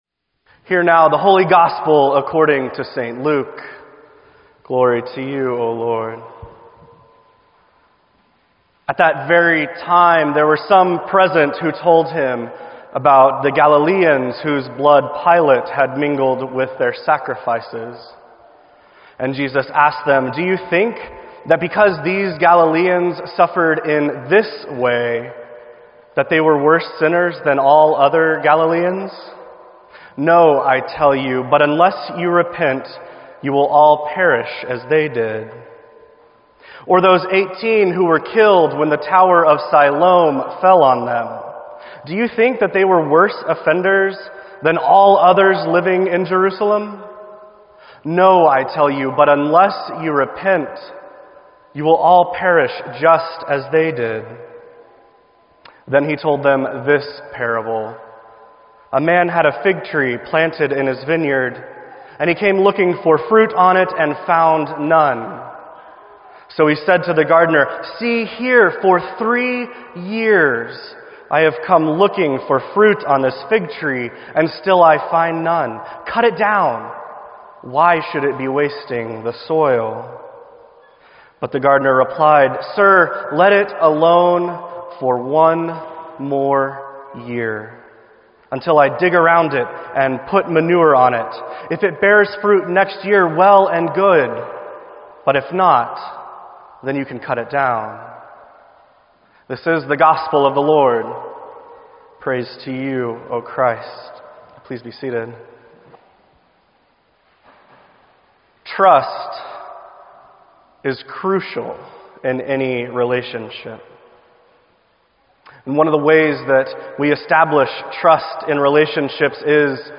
Sermon_2_28_16.mp3